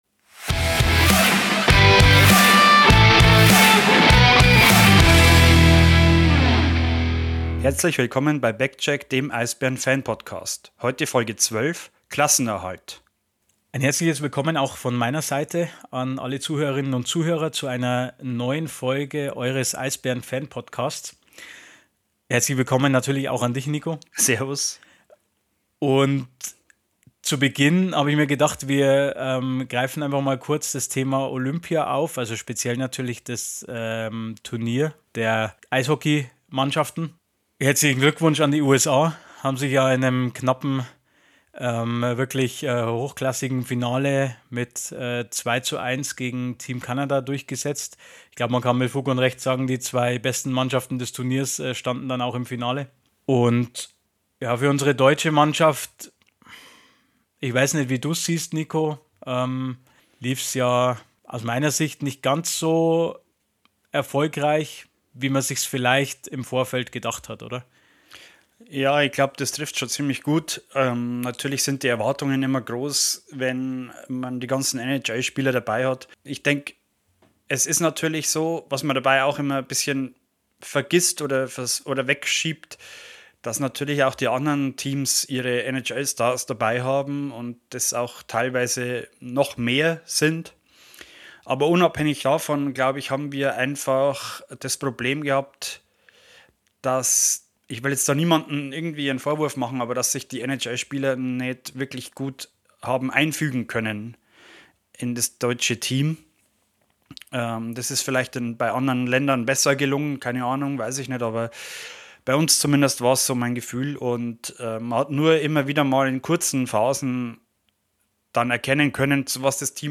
In der heutigen Folge sprechen wir über die besondere Atmosphäre beim Retro-Spieltag. Außerdem gibt es nochmal alles rund um die Sonderzug-Auswärtsfahrt nach Bietigheim, inklusive eurer Stimmen zu und einigen Einspielern von diesem historischen Eisbären-Event. Im Sch(l)ussblock blicken wir wie gewohnt in aller Kürze auf die Liga im Allgemeinen und möchten euch außerdem die anstehenden Playdown-Spiele der DNL-Mannschaft ans Herz legen.